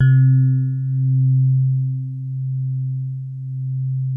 TINE SOFT C2.wav